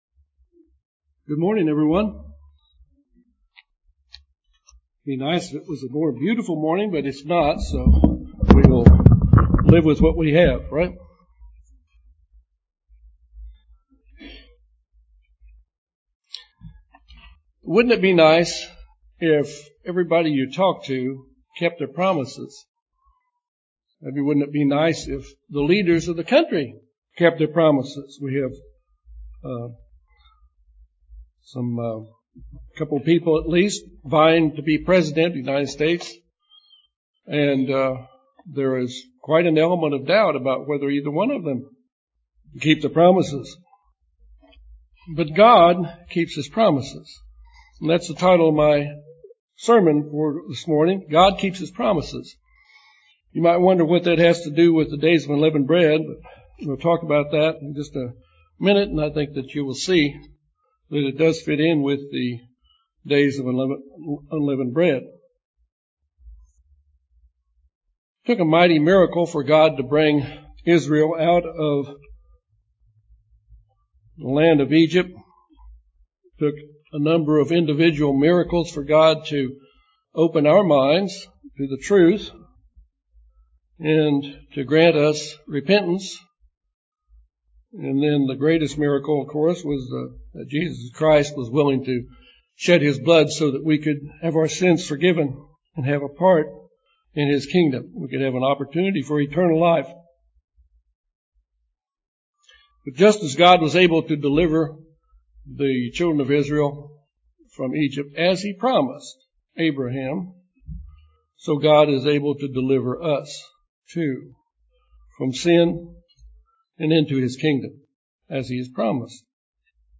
This sermon deals with how God kept His promises to Abraham, Israel and will keep His promises to us.